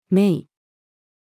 命-order-female.mp3